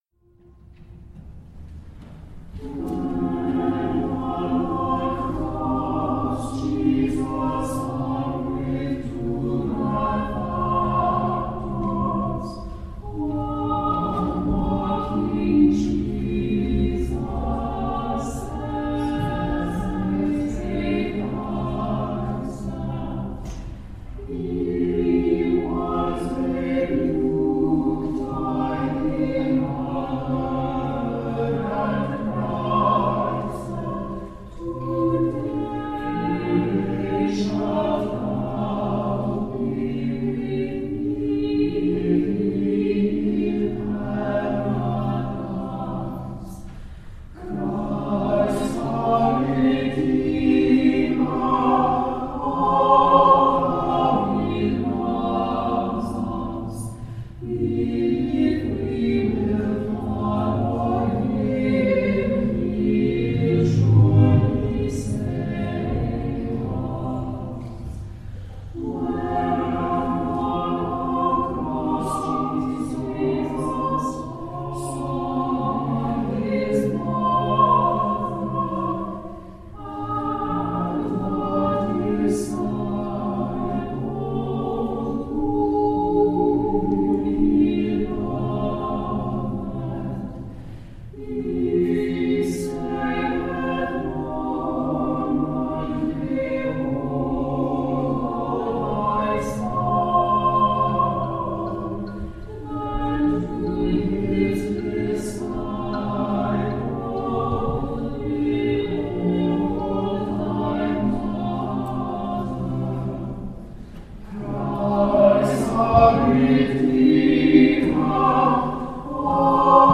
Good Friday, 2019